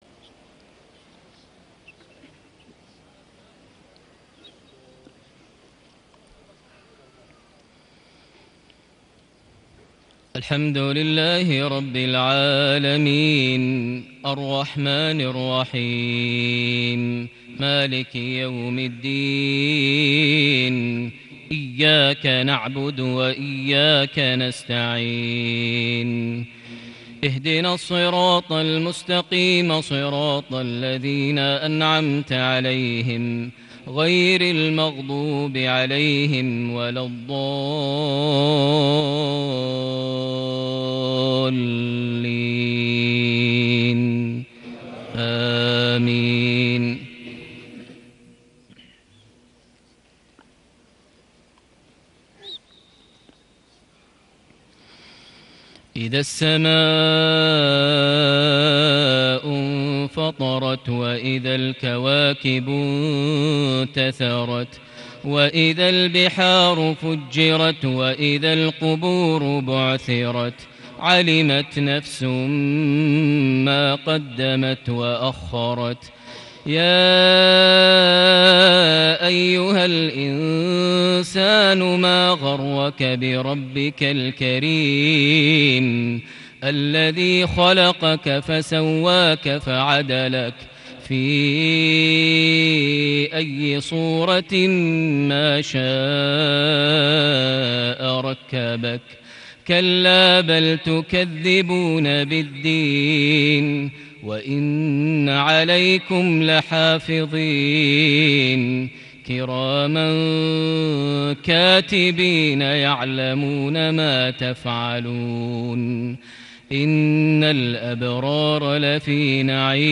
صلاة العشاء ٢٦ ربيع الآخر ١٤٣٨سورتي الإنفطار - الطارق > 1438 هـ > الفروض - تلاوات ماهر المعيقلي